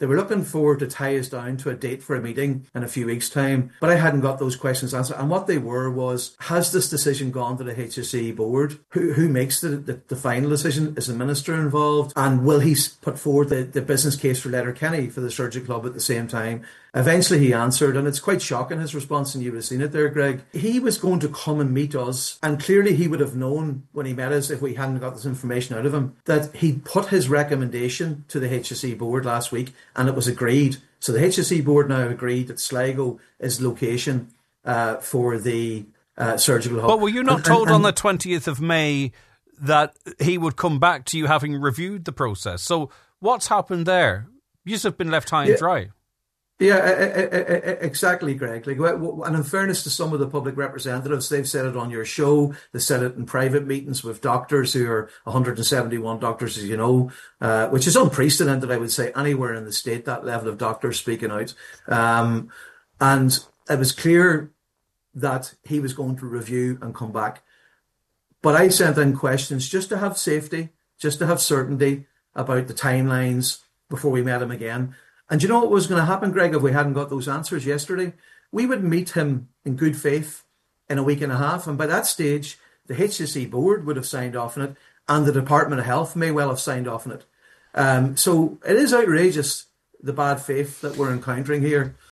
on this morning’s Nine ’til Noon Show